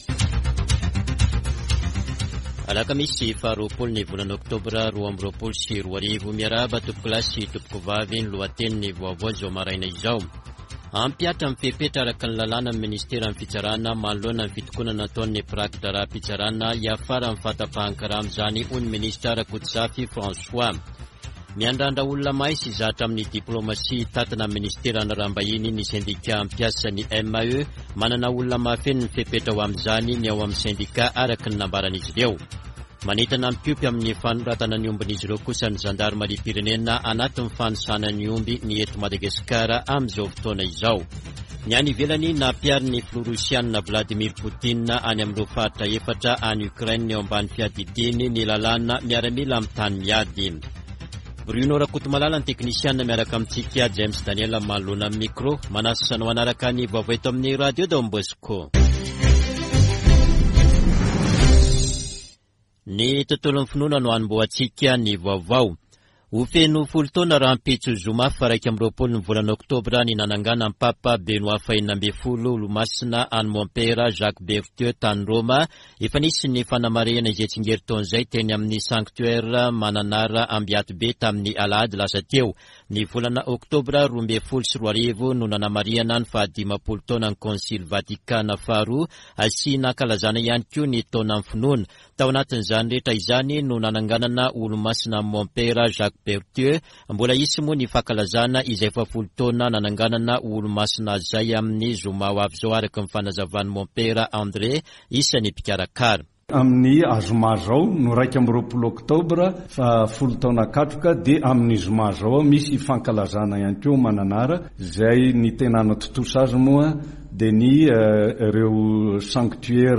[Vaovao maraina] Alakamisy 20 ôktôbra 2022